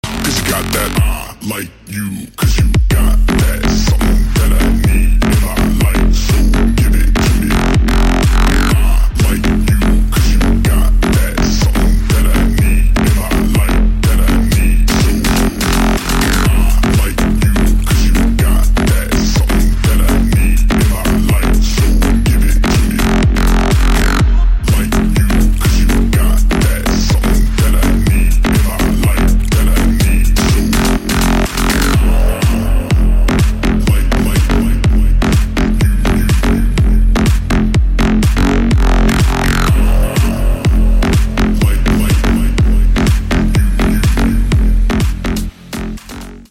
• Качество: 128, Stereo
громкие
EDM
мощные басы
Bass House
низкий мужской голос
Groove House